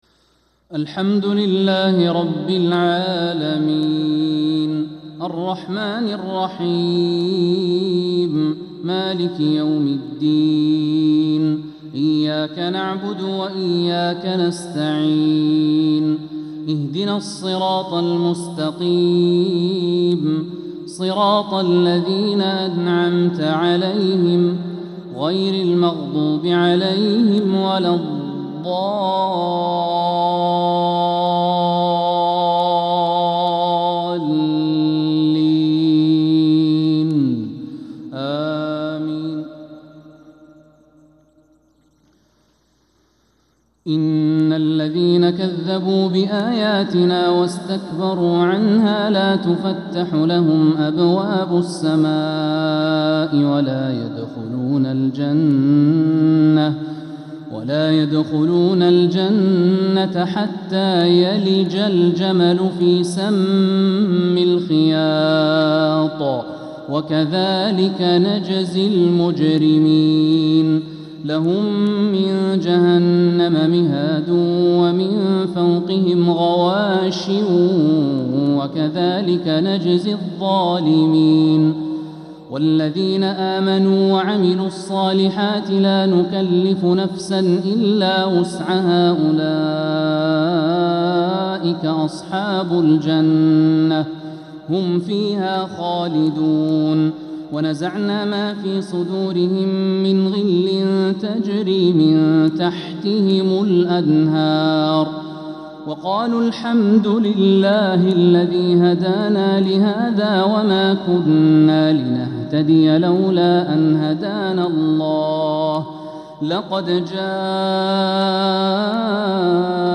فجر الثلاثاء 13 محرم 1447هـ من سورة الأعراف 40-53 | Fajr prayer from Surat Al-Araf 8-7-2025 > 1447 🕋 > الفروض - تلاوات الحرمين